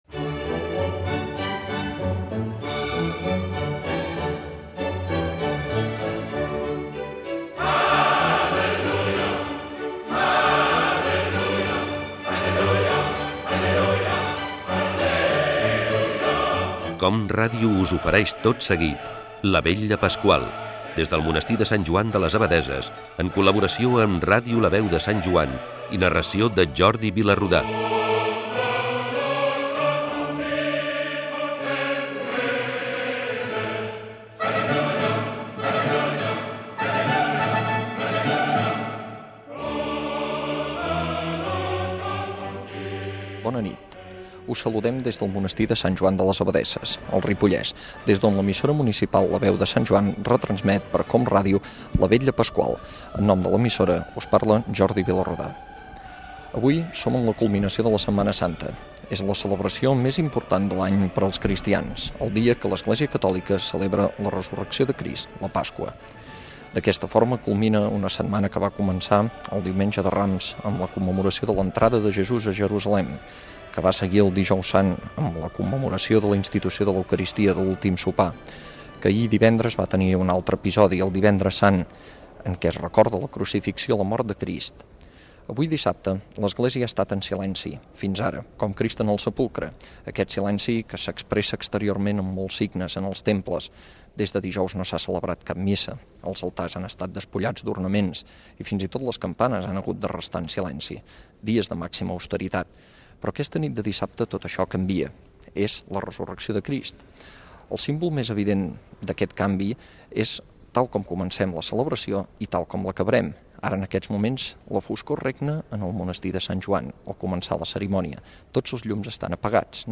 Careta del programa i transmissió, des del Monestir de Sant Joan de les Abadesses, de la Vetlla Pasqual
Amb la participació de la Coral de Monestir
Religió